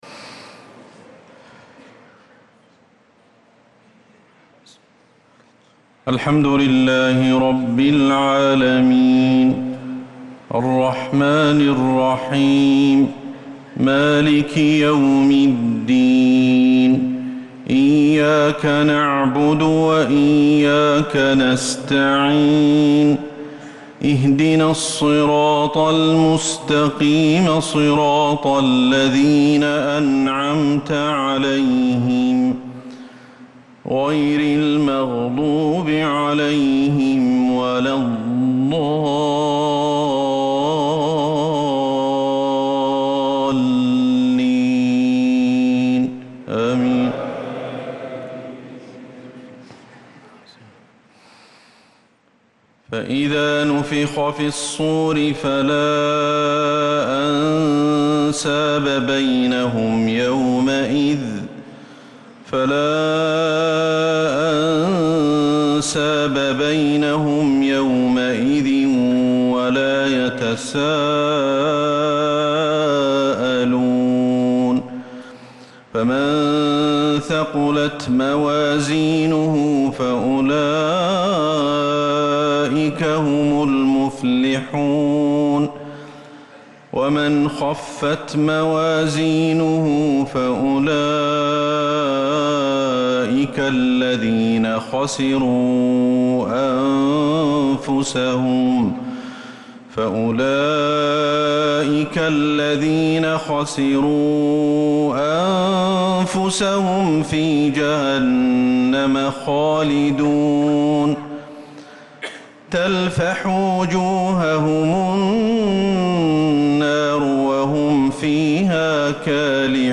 صلاة العشاء للقارئ أحمد الحذيفي 19 ذو الحجة 1445 هـ
تِلَاوَات الْحَرَمَيْن .